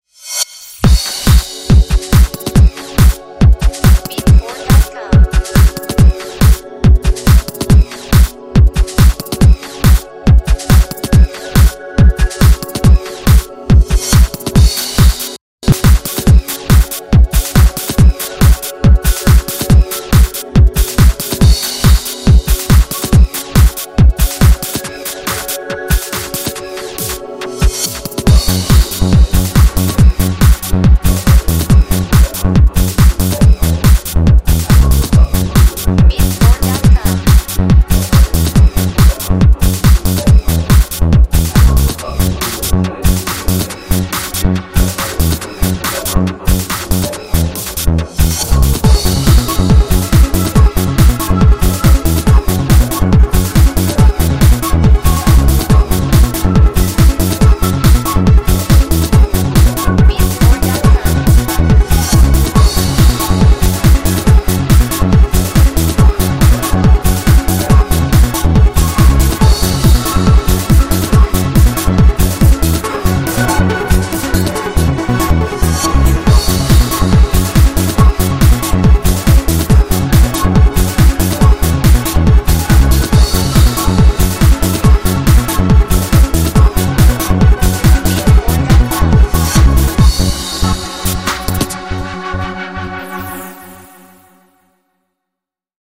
Genre: Tech-Trance Mood: Hypnotic Intense Futuristic
Time Signature: 4/4
Instruments: Synthesizer Vocal